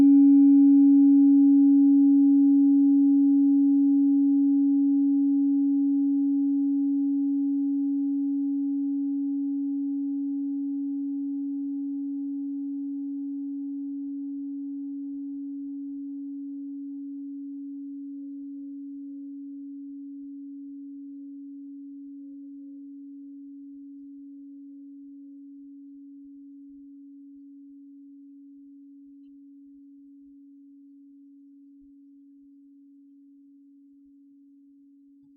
Klangschalen-Typ: Bengalen und Tibet
Klangschale 5 im Set 5
Klangschale Nr.5
(Aufgenommen mit dem Filzklöppel/Gummischlegel)
klangschale-set-5-5.wav